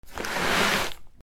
布ずれ（引きずる）
/ J｜フォーリー(布ずれ・動作) / J-05 ｜布ずれ